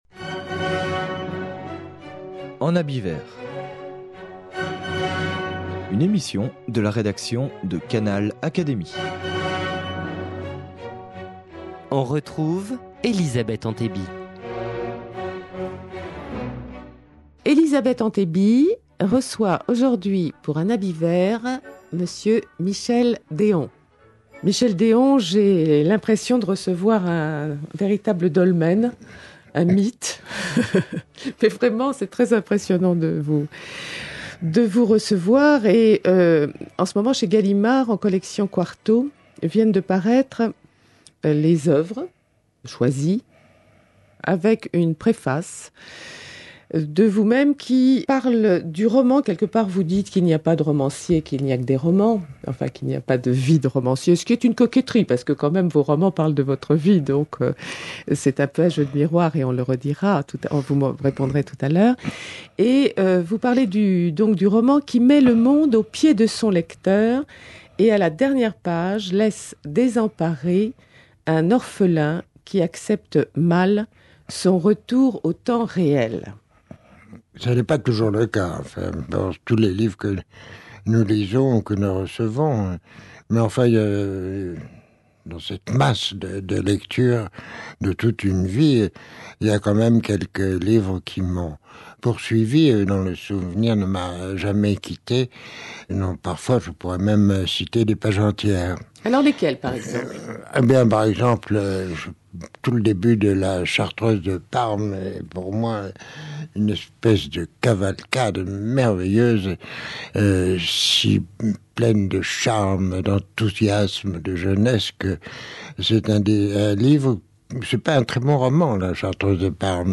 Pour Canal Académie, Michel Déon, à la suite de notre entretien, a accepté de nous en lire une.